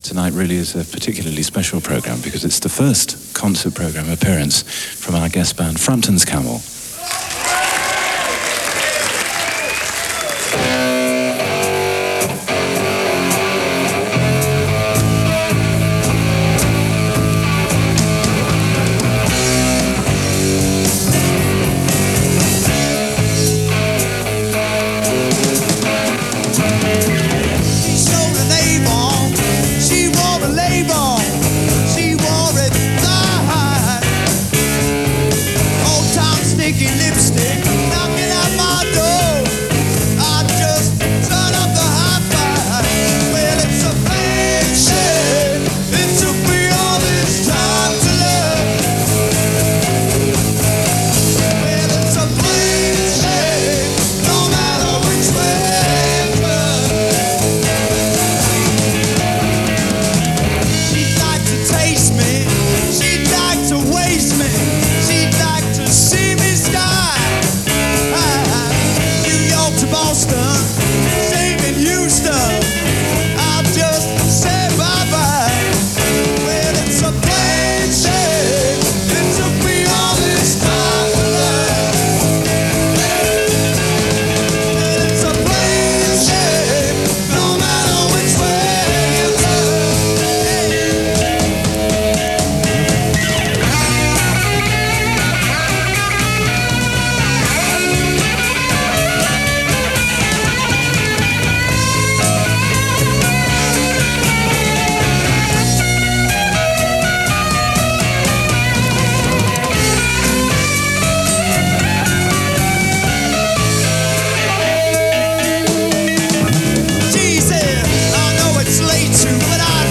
live at The Paris Theatre, London